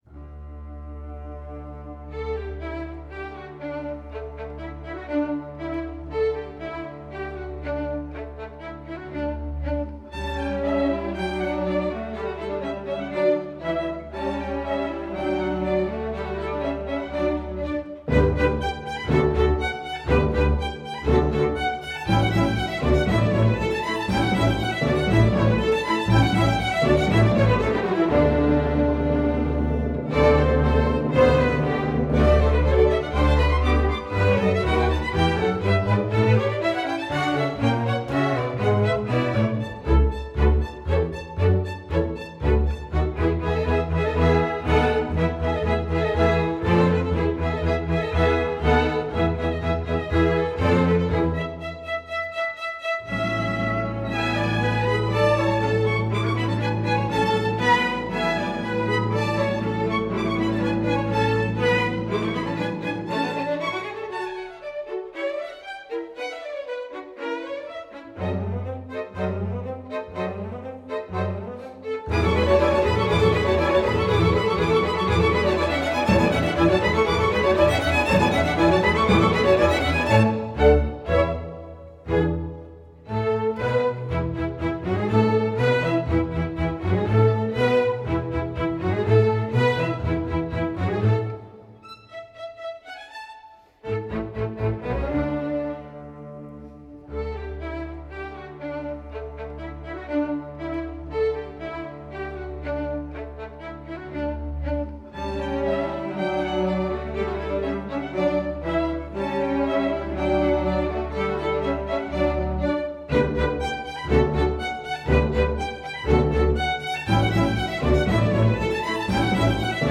Category: String Orchestra
Instrumentation: str=8.8.5.5.5,(timp)